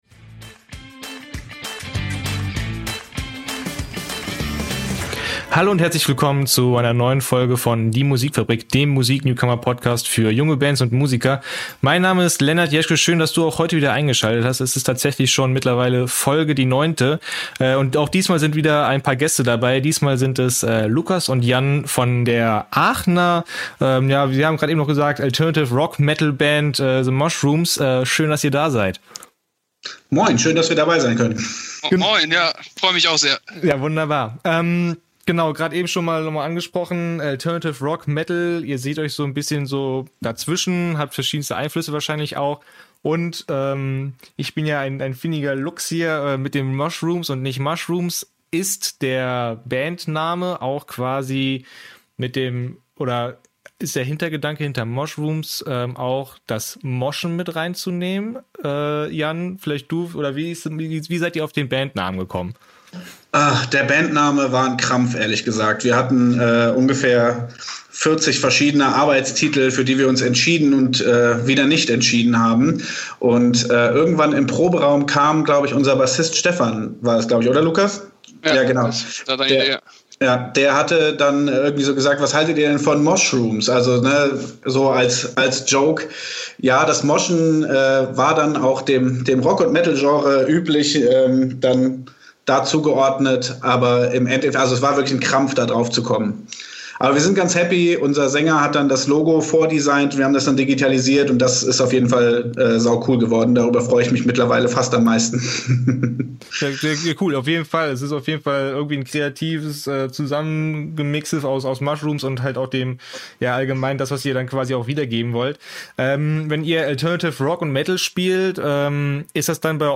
Juni 2021 Nächste Episode download Beschreibung Teilen Abonnieren Die Musikfabrik - Der Musik-Newcomer Podcast: In Folge 9 geht´s ins Rheinland.